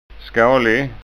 SKÅNSKA UTTAL